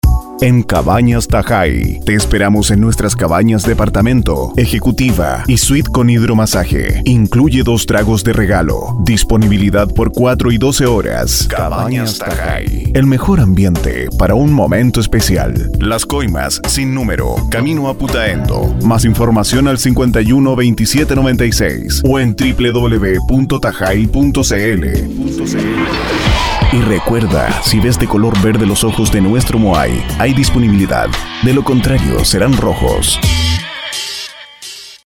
Voz en Off
Tahai  - Campaña radial (2012)